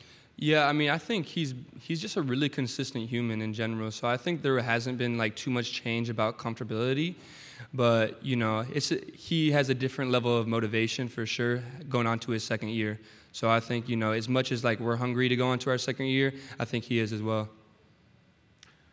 Media Days are being held at the Nashville Grand Hyatt Hotel in Nashville, Tennessee.